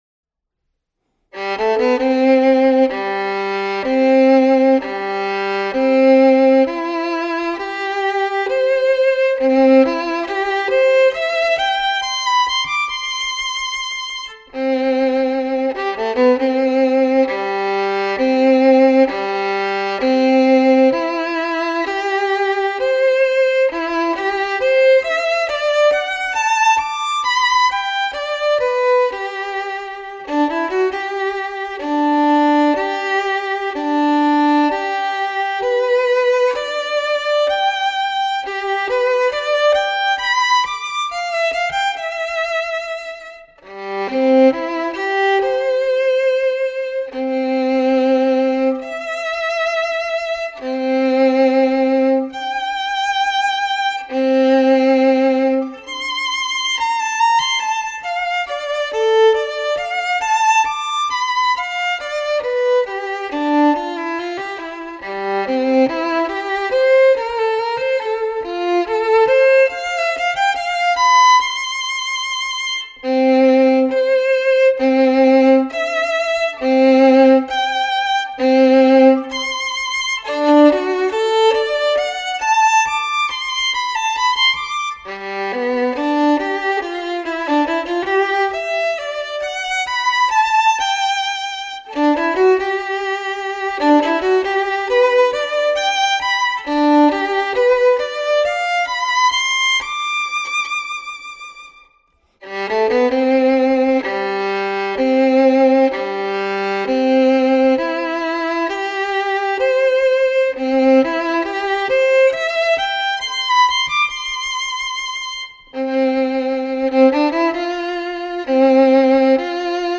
Una selección de estudios del libro 60 estudios para violín op. 45 del violinista y pedagogo alemán F. Wolfhart, en la edición de Frigyes Sándor.